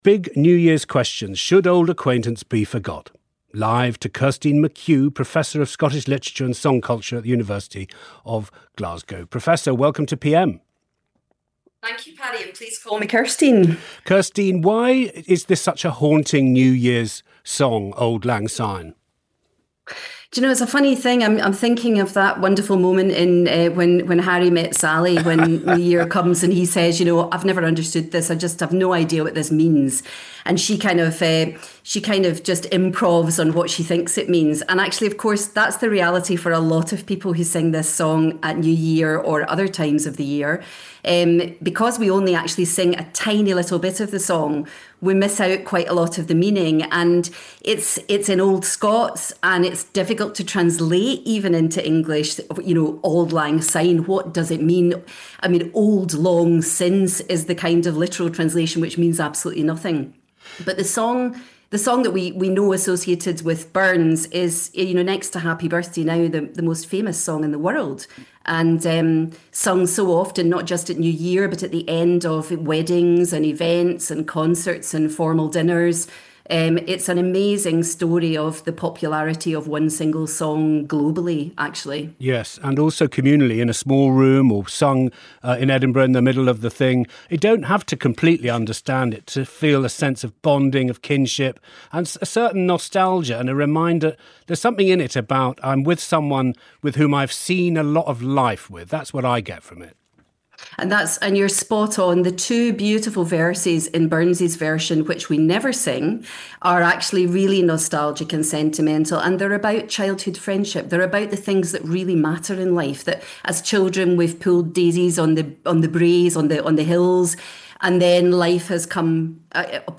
BBC Radio 4 PM interview